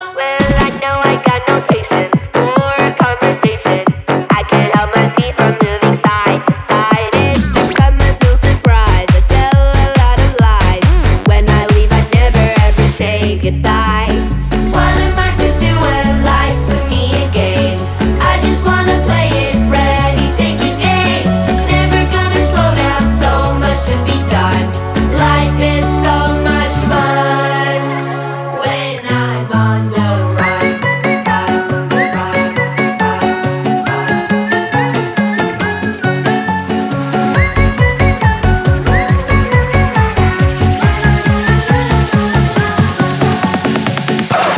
позитивные
свист
детский голос
house
Melbourne Bounce
Позитивная танцевальная музыка